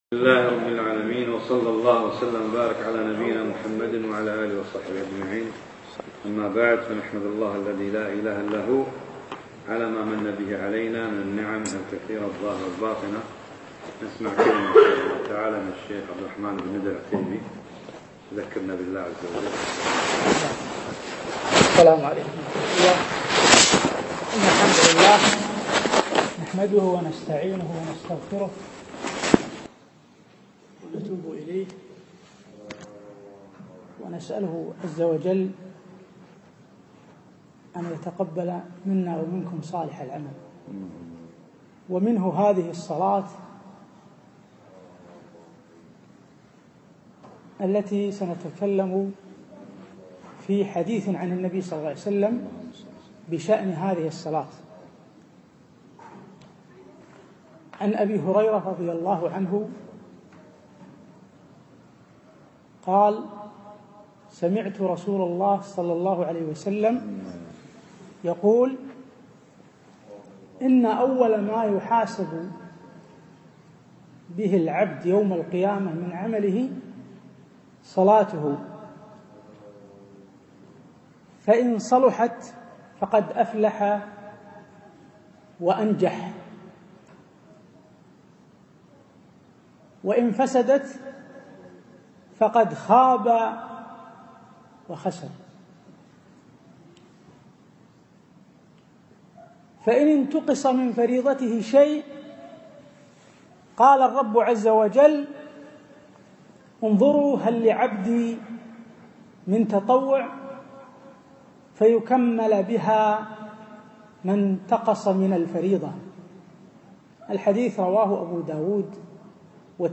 كلمة ألقيت في مسجد المخيال العارضية